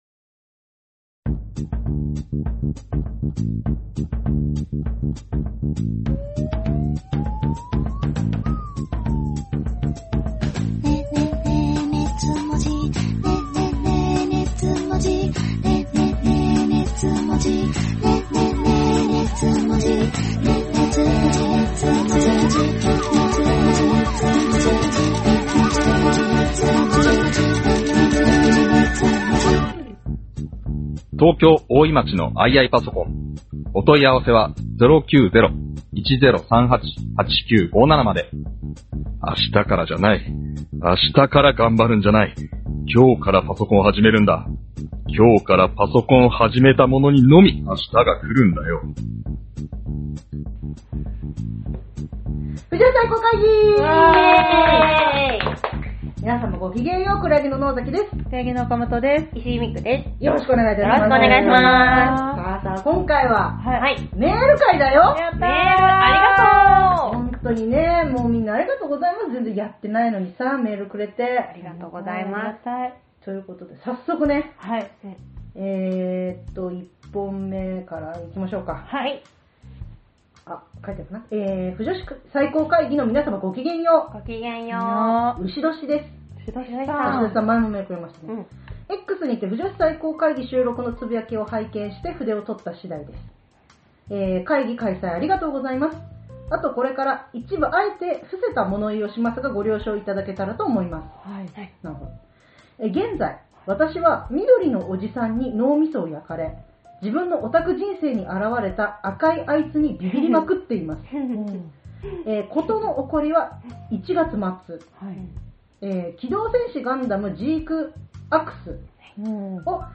オタク芸人 サンキュータツオ Presents 二次元を哲学するトークバラエティ音声マガジン『熱量と文字数』のブログです。